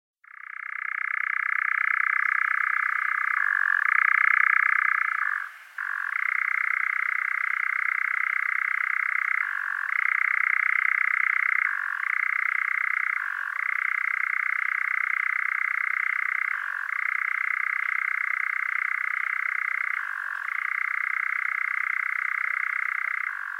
Вы можете слушать онлайн или скачать трели, крики и другие голосовые проявления козодоя в формате mp3.
Самец козодоя